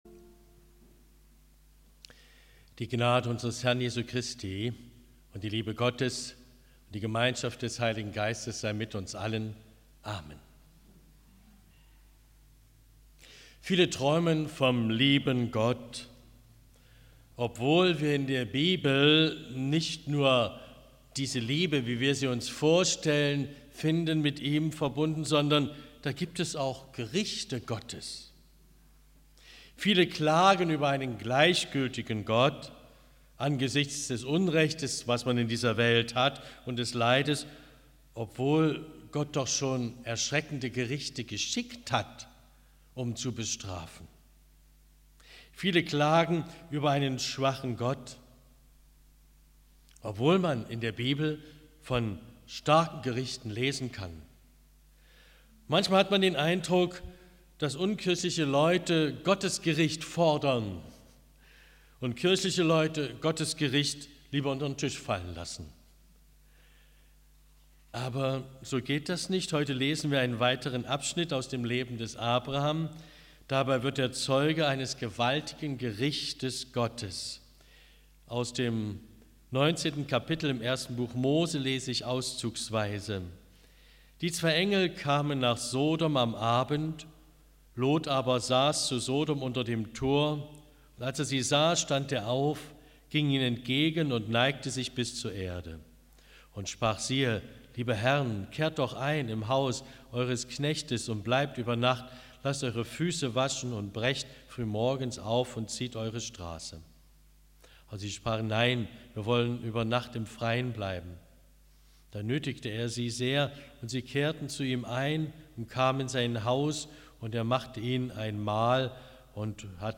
Predigt 26.06.2022